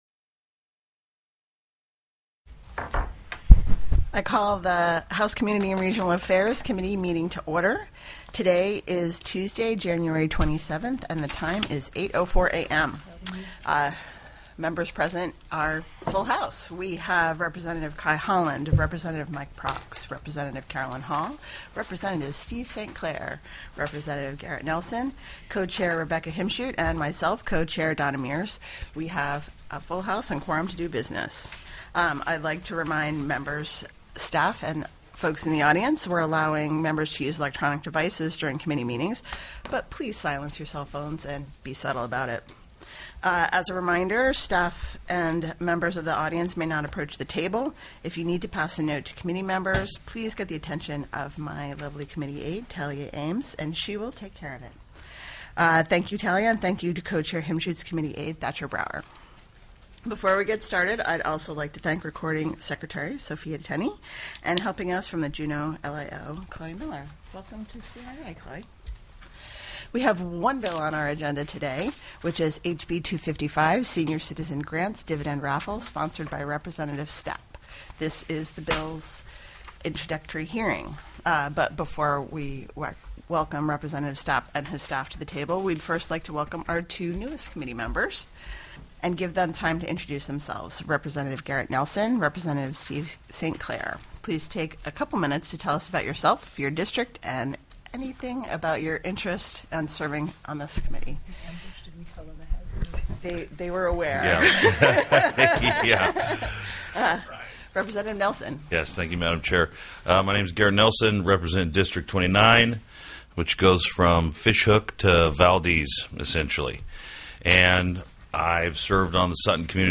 The audio recordings are captured by our records offices as the official record of the meeting and will have more accurate timestamps.
REPRESENTATIVE WILL STAPP, Alaska State Legislature, as prime sponsor, presented HB 255 . He paraphrased the sponsor statement [included in the committee file], which read as follows [original punctuation provided]: House Bill 255 creates a new, voluntary way for Alaskans to support senior services across the state by establishing the Senior Citizen Grants Endowment Fund and the Senior Citizen Grants Dividend